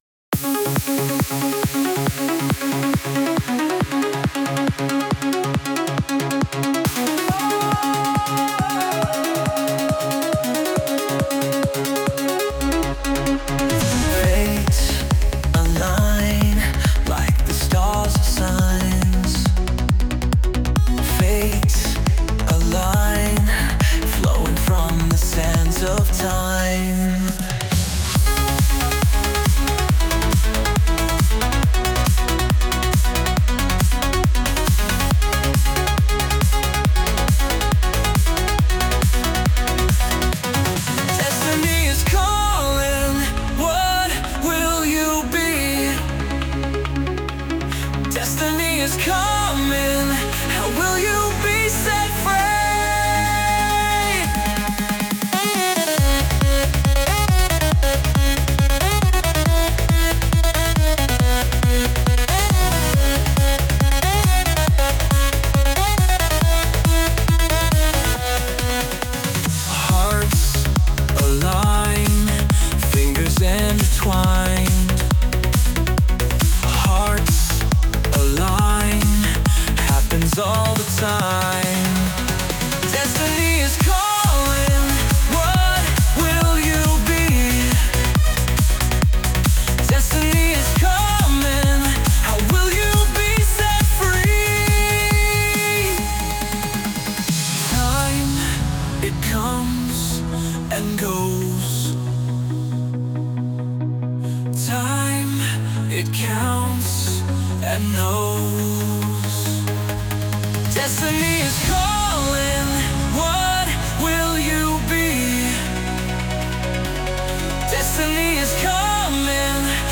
Let this trance track guide you to better and more.........